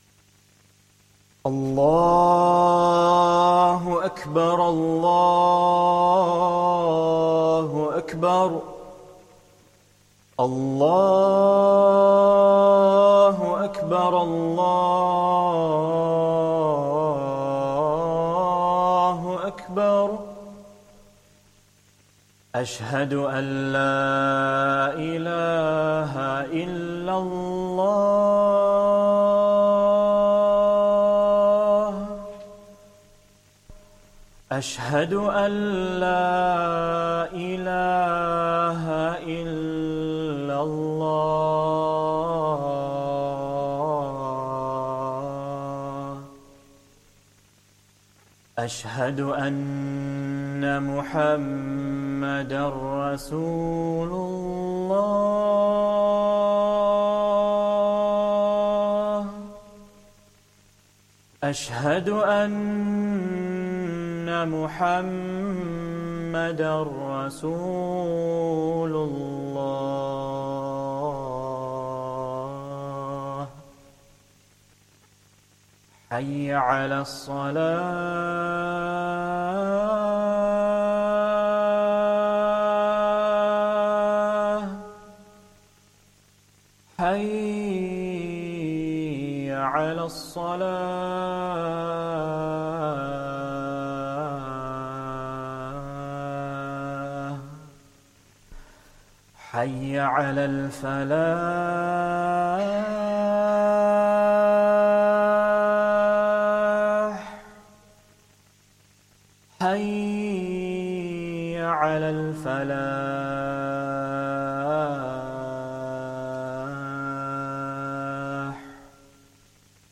Esha Talk & Jammat